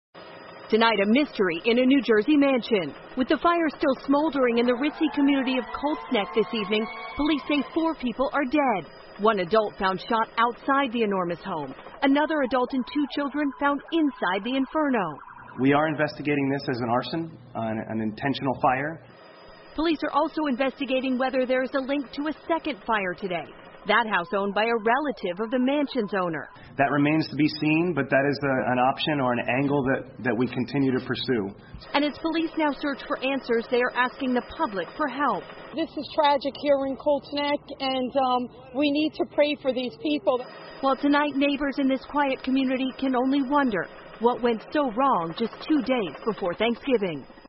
NBC晚间新闻 新泽西小镇突发火灾 听力文件下载—在线英语听力室